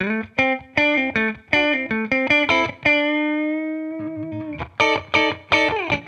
Index of /musicradar/sampled-funk-soul-samples/79bpm/Guitar
SSF_TeleGuitarProc2_79D.wav